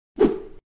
Whoosh SFX -
Whoosh Sound Effect (8)
Whoosh-Sound-Effect-8.mp3